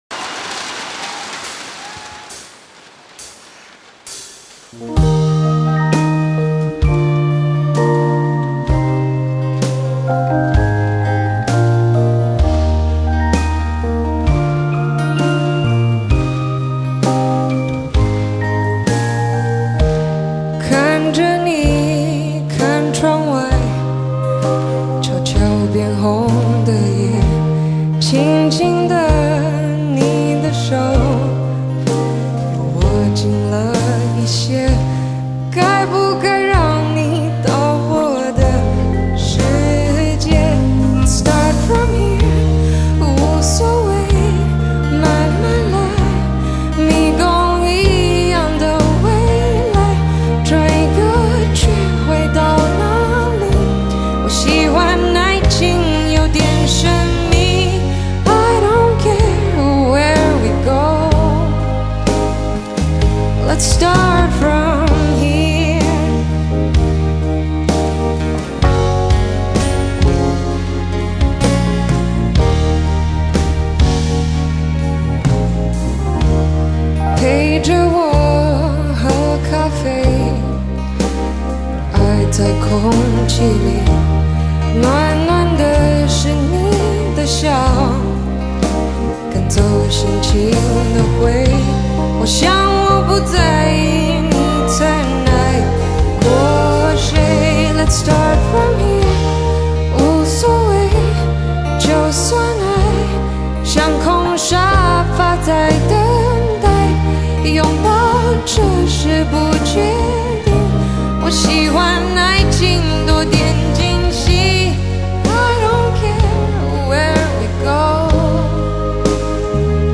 DVD/CD同步收錄20首演唱會現場精彩曲目